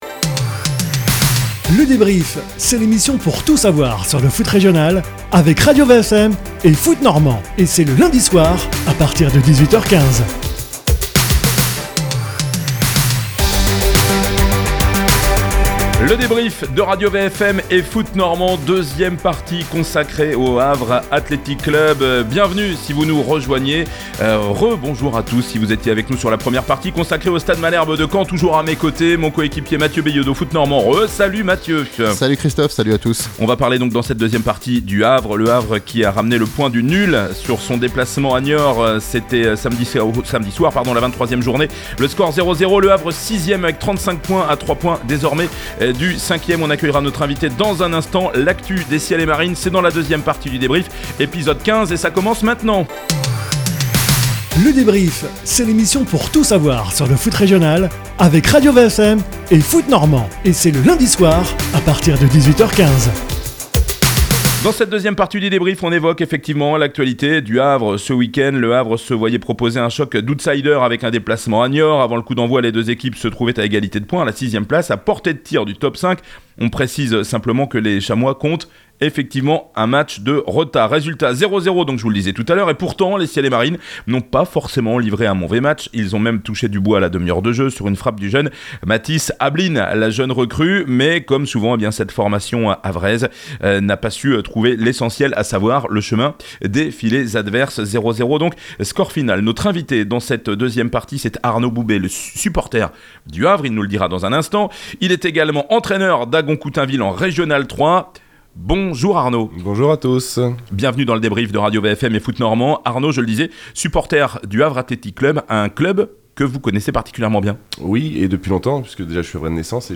On donne la parole à des supporters, des supporters un peu spéciaux avec : pour le Havre Athletic Club FA ,